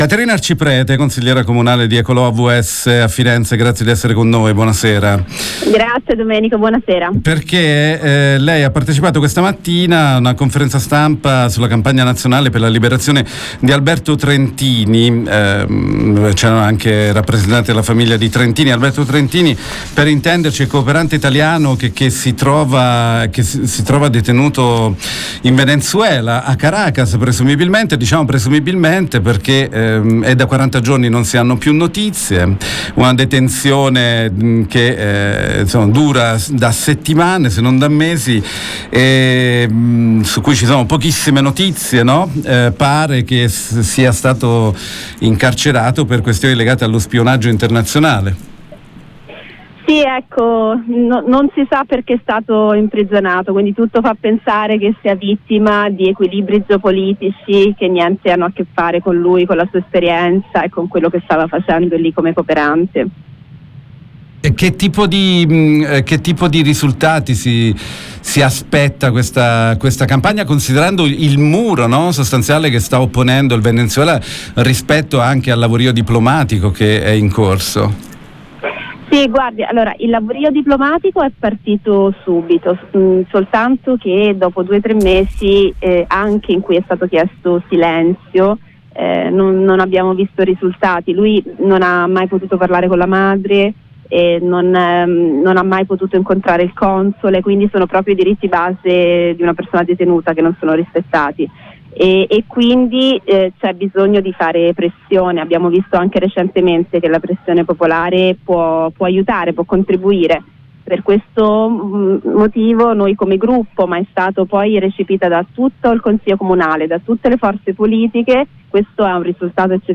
L’abbiamo intervistata